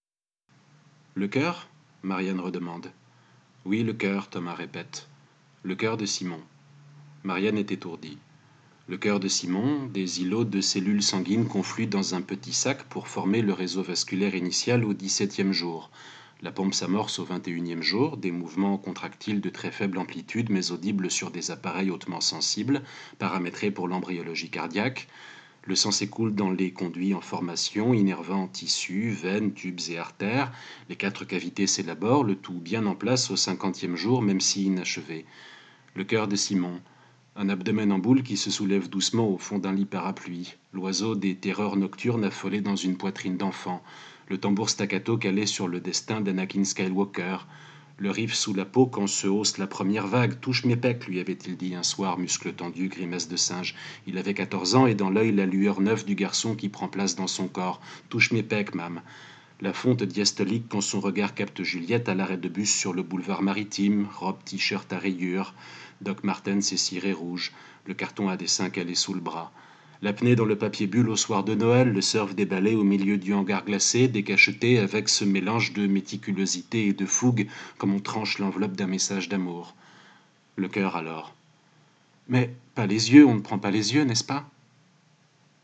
Pour l’occasion, et parce que j’ai vraiment aimé ce que j’ai lu, plutôt que juste en reproduire les photos j’ai choisi de vous lire les pages en question :